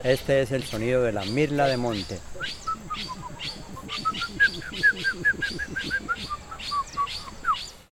Sonido de la mirla (ave)
Sonido de la mirla.mp3 (187.35 KB)
Grabación de la imitación del sonido de la mirla, ave que habita el Valle del Cauca.